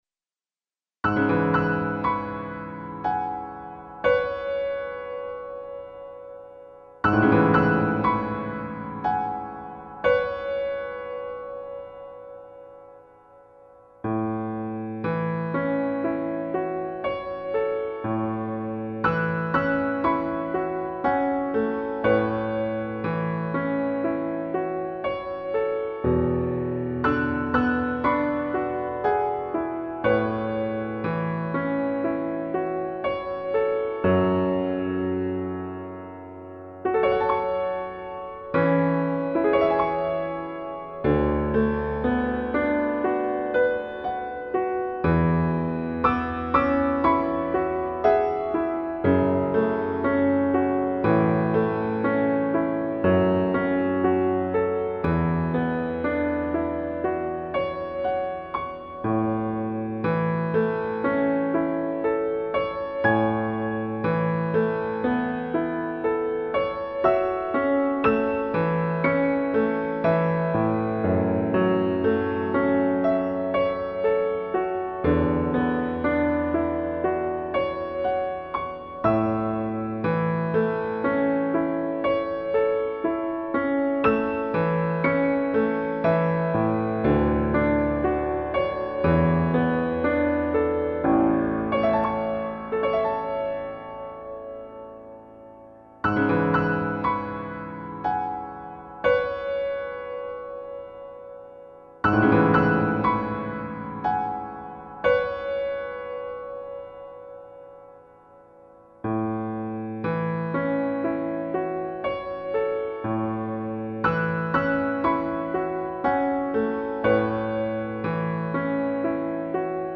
A调伴奏：